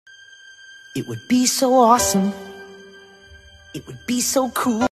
Fruit Ninja Sound Effects On Sound Effects Free Download